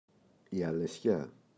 αλεσιά, η [aleꞋsça]